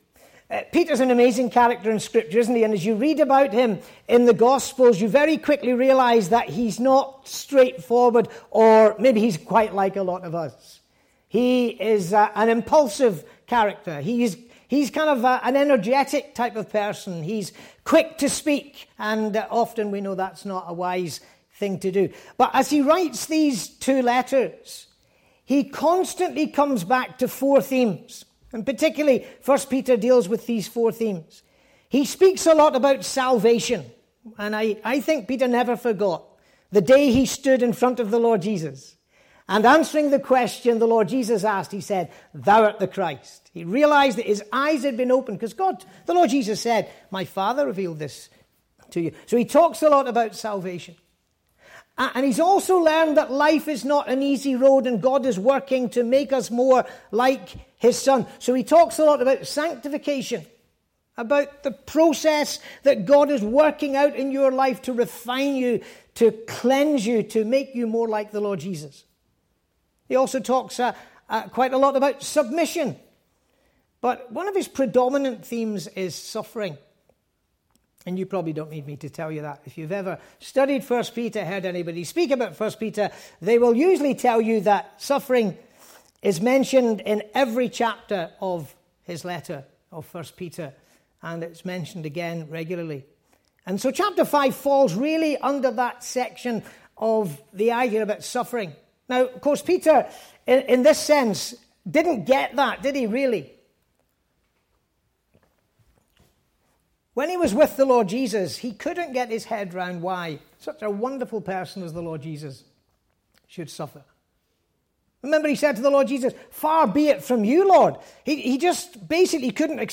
Saturday Night Ministry 2024-5 Passage: 1 Peter 5:7